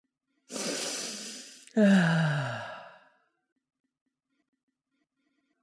Tiếng Hít Hà…
Thể loại: Tiếng con người
Description: Hiệu ứng âm thanh tiếng hít hà, xuýt xoa, sụt sịt vì vừa cay, xuýt xoa ấy vang dội quanh bàn ăn, như minh chứng cho sự ngon quá, đã quá, cay quá... khiến từng hơi thở cũng đậm đà hương vị...
tieng-hit-ha-www_tiengdong_com.mp3